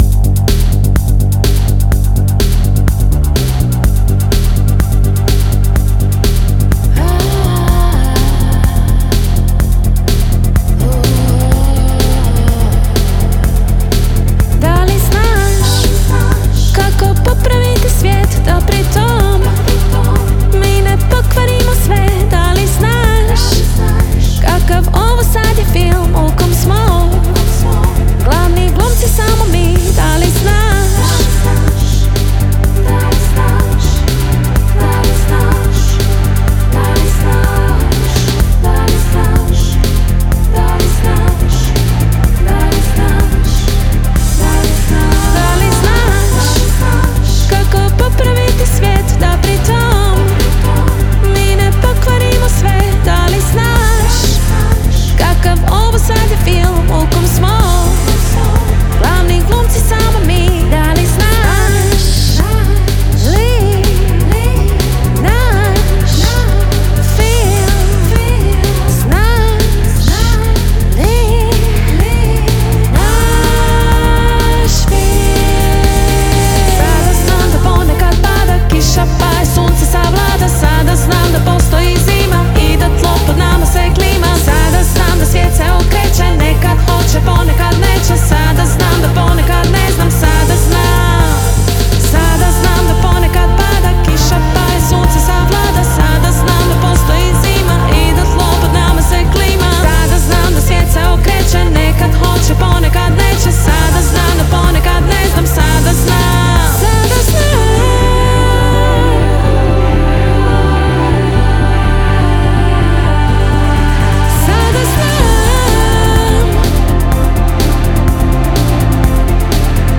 svojim beatom poput brzog vlaka